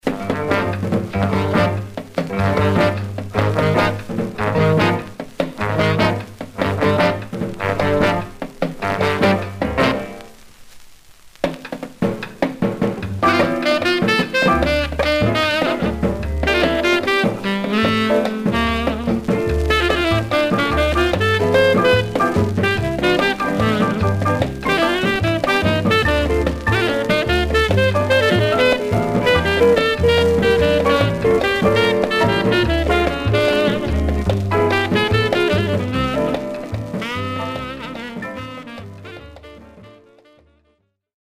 Some surface noise/wear
Mono
R&B Instrumental Condition